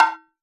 PERC - BLANK.wav